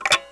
Percussus    The Drum Machine
A drum machine made with midi controlled relays.
At the left is a guitar pickup that amplifies the percussion like, click clack sounds of the relays.
Both a microphone and contact pickup is used to amplify the sounds to produce a stereo signal with a mixer circuit.